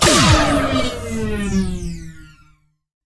ogg / ships / combat / torp.ogg